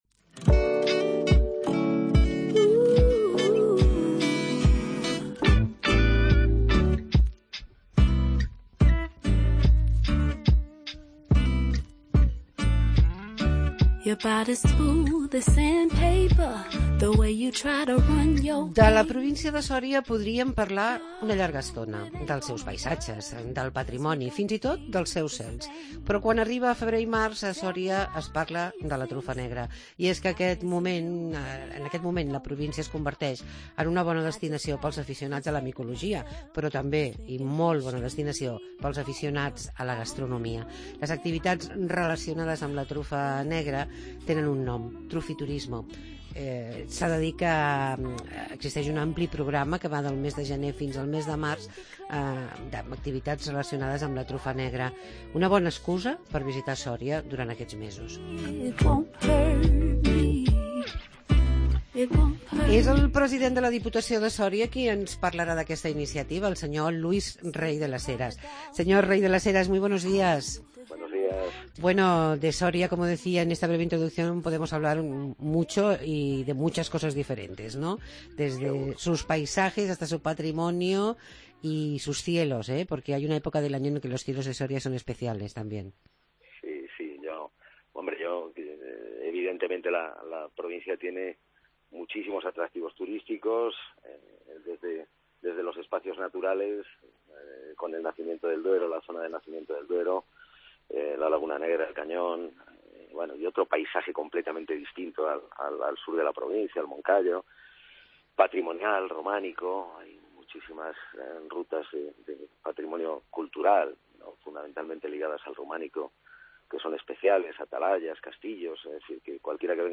Luis Rey de las Heras, presidente de la Diputación de Soria nos explica que es el Trufiturismo